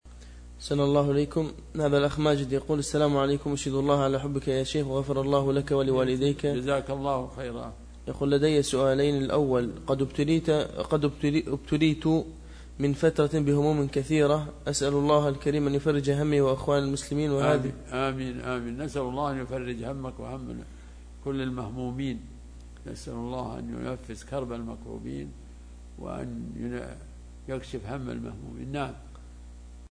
دعاء الشيخ لتفريج الهموم